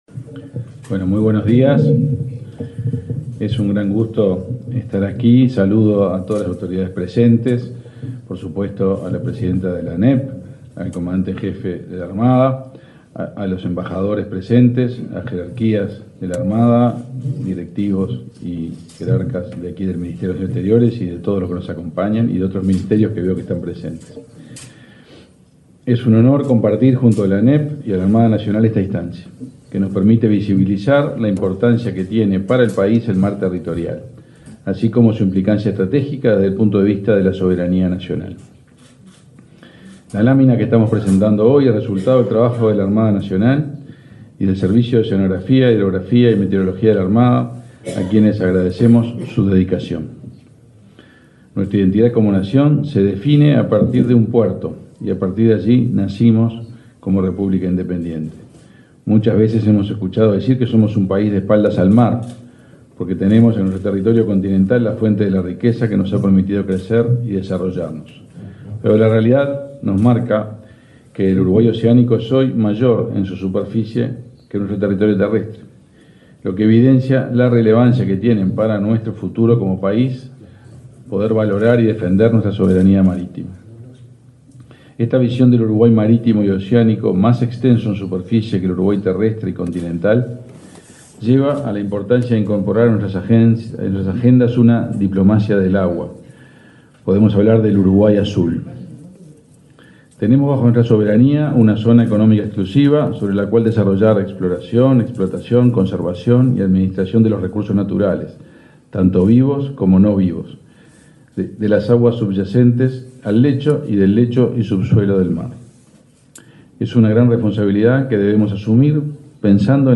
Palabras de autoridades en acto en el Ministerio de Relaciones Exteriores
Este lunes 9 en la sede del Ministerio de Relaciones Exteriores, el canciller Omar Paganini; el comandante en jefe de la Armada Nacional, Jorge Wilson, y la presidenta de la Administración Nacional de Educación Pública, Virginia Cáceres, participaron en la presentación de una lámina del Uruguay con sus jurisdicciones marítimas, realizada por el Servicio de Oceanografía, Hidrografía y Meteorología de la Armada Nacional.